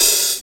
Wu-RZA-Hat 59.wav